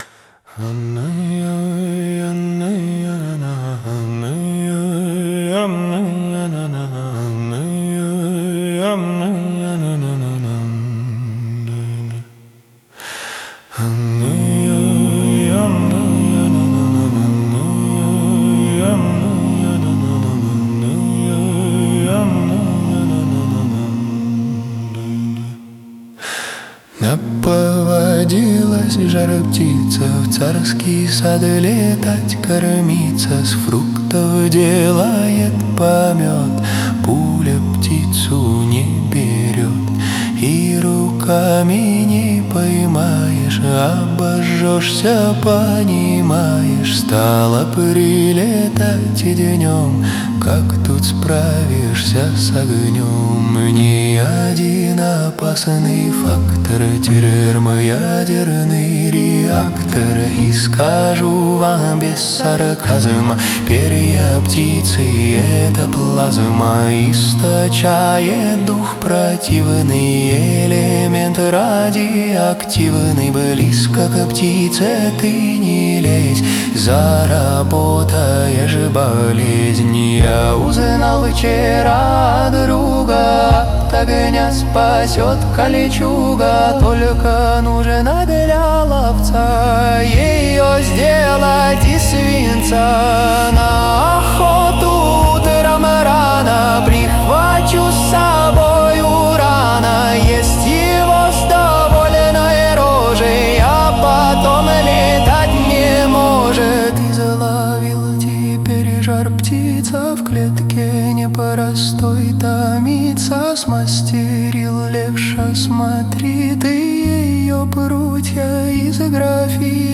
Будь он покороче, его можно было бы назвать частушкой.